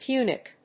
音節Pu・nic 発音記号・読み方
/pjúːnɪk(米国英語), ˈpju:nɪk(英国英語)/